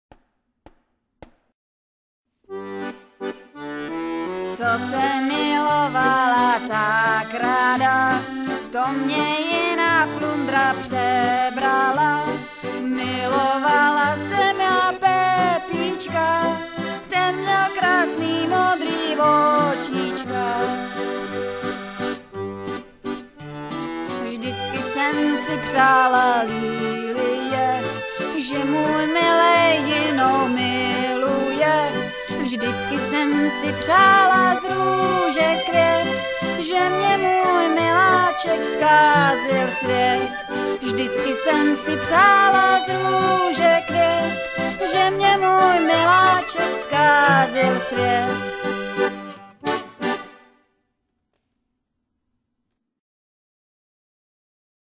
hrají a zpívají
Lidová / Lidová